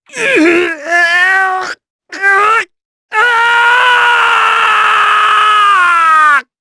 Clause-Vox-Story-Pain_1_jp.wav